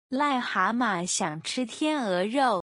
“You Swan, He Frog” can be traced back to an old Chinese saying “癞蛤蟆想吃天鹅肉” (lài há ma xiǎng chī tiàn é ròu) which literally translates to “a toad wants to eat swan meat” and means that someone is craving something they are not worthy of (e.g. an ugly man wants to be with a beautiful woman).